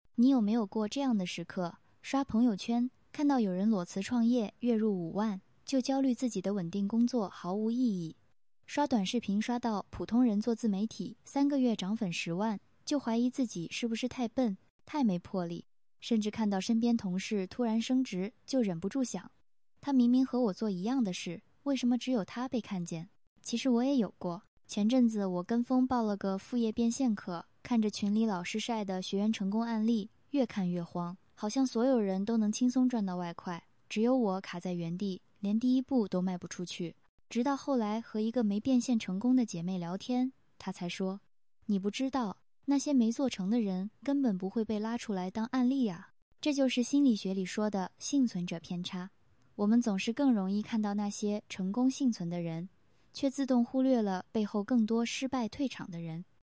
第二步，AI配音 剪映剪辑成片 配音的选择比较多，有剪映会员的伙伴可以选择剪映里面进行配音，也可以利用第三方工具（配音蜂、minimax等）进行配音。
用MiniMax语音一句话生成古代才女独白，涨粉+变现双双起飞 配音效果如下 幸存者偏差 配音解决之后，将图片素材和配音导入剪映，剪辑成片即可。